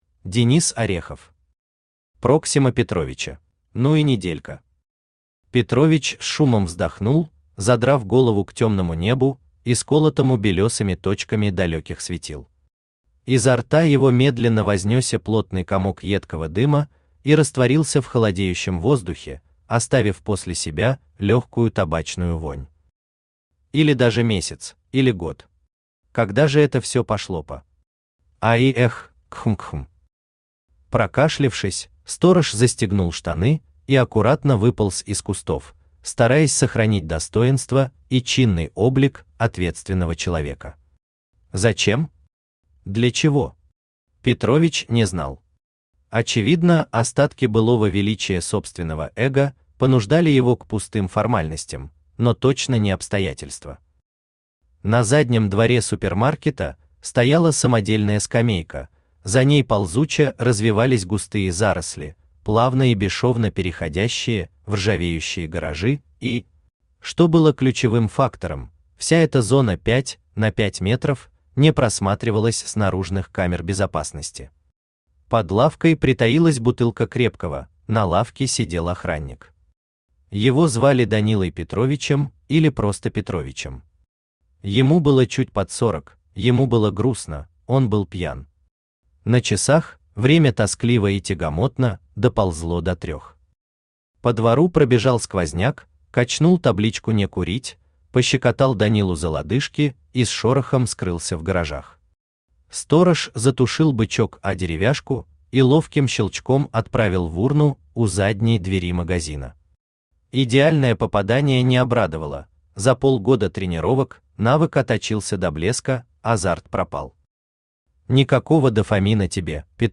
Аудиокнига Проксима Петровича | Библиотека аудиокниг
Aудиокнига Проксима Петровича Автор Денис Орехов Читает аудиокнигу Авточтец ЛитРес.